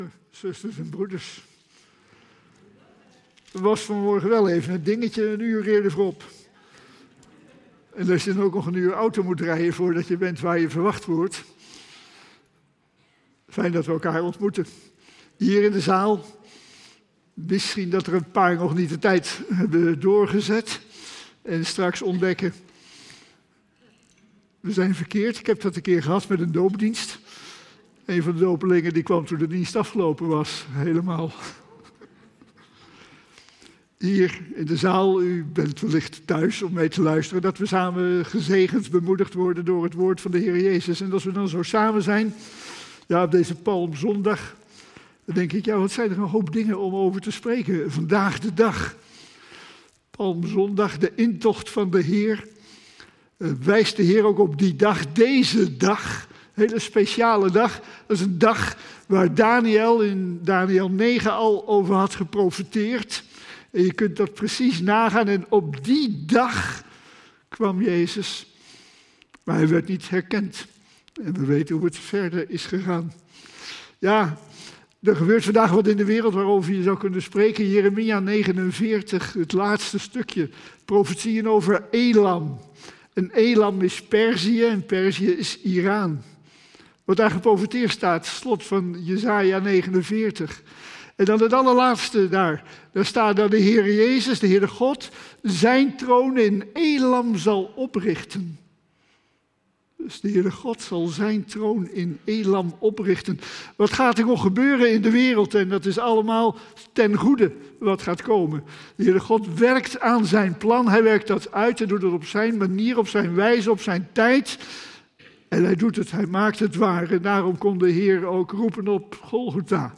15-30 Het thema van de preek is: "De Glorie van het Kruis".